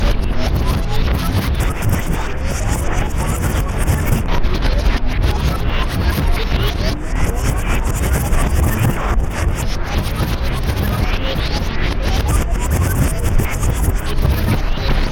reverse fractured texture scary.ogg
Original creative-commons licensed sounds for DJ's and music producers, recorded with high quality studio microphones.
Channels Stereo